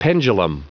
Prononciation du mot pendulum en anglais (fichier audio)
Prononciation du mot : pendulum